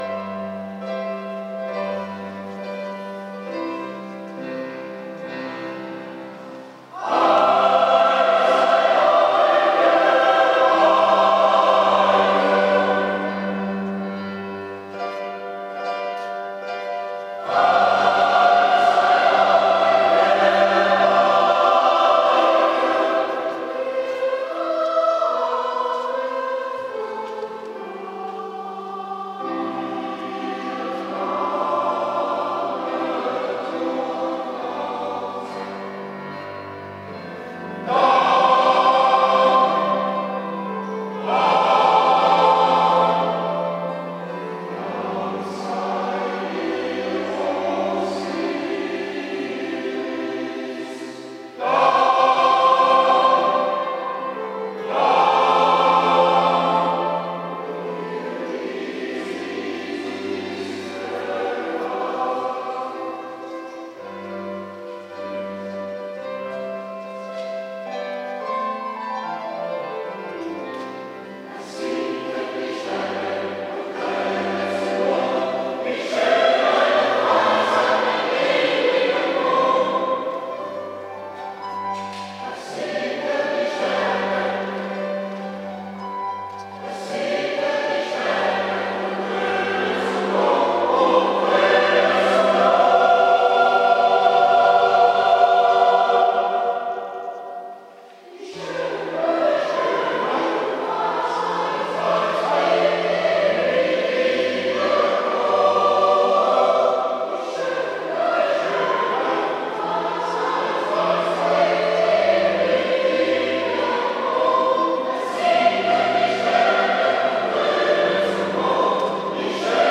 GKZ St. Caecilia – R.K. GEMENGD KERKELIJK ZANGKOOR “ST. CAECILIA” PETRUS EN PAULUS – PAROCHIE TE SCHAESBERG – LANDGRAAF